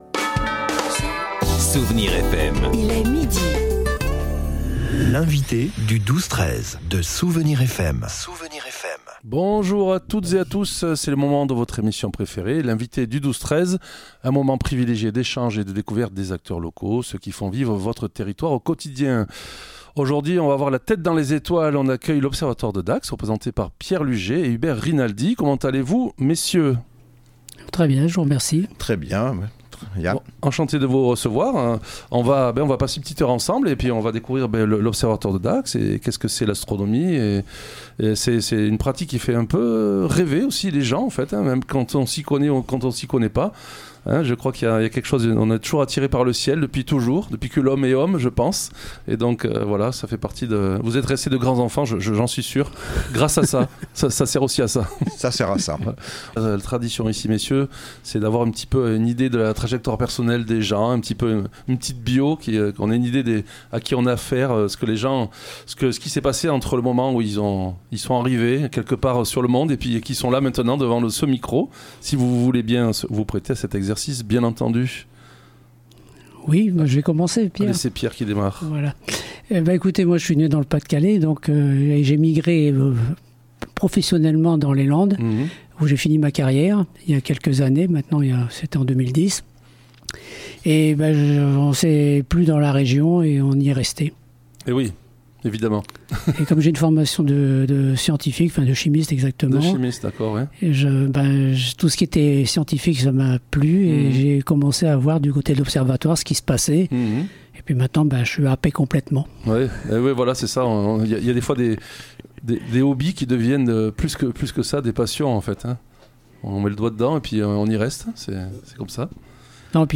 L'invité(e) du 12-13 de Soustons recevait aujourd'hui l'Observatoire de Dax.